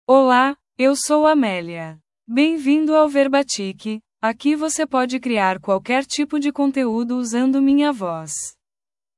FemalePortuguese (Brazil)
AmeliaFemale Portuguese AI voice
Voice sample
Listen to Amelia's female Portuguese voice.
Female
Amelia delivers clear pronunciation with authentic Brazil Portuguese intonation, making your content sound professionally produced.